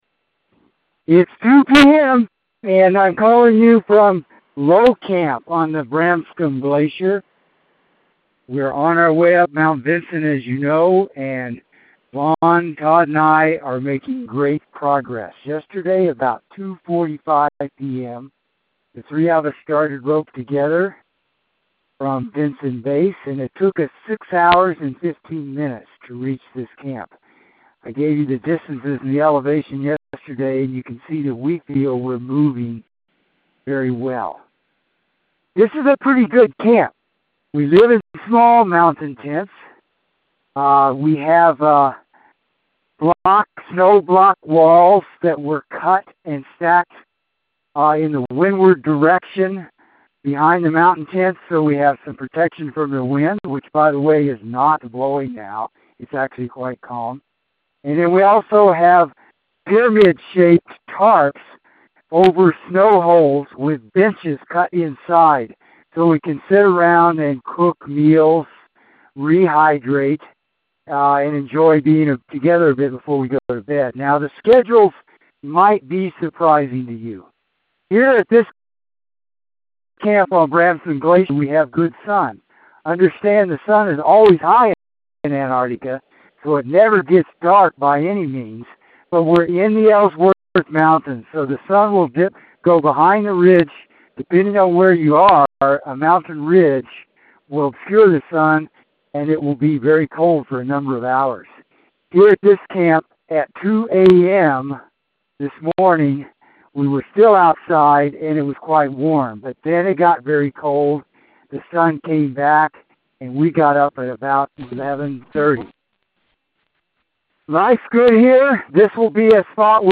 Expedition Dispatch